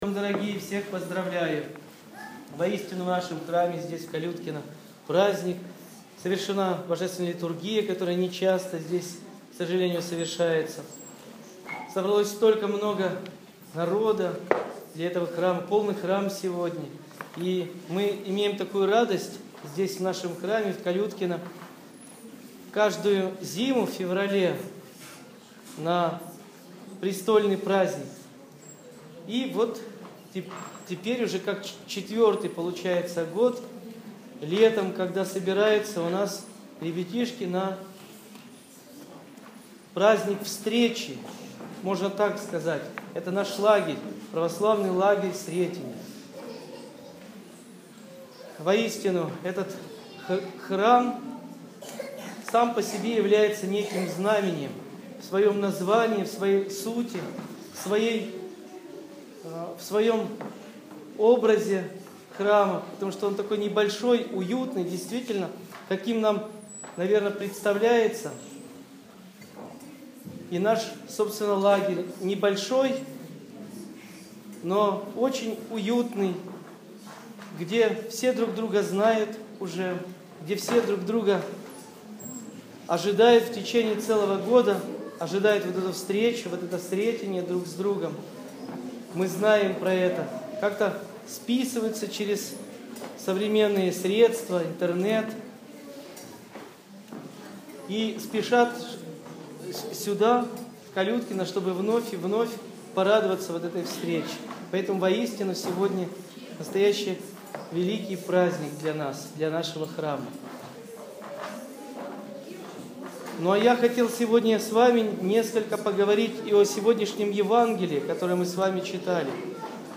Проповедь настоятеля после Божественной Литургии.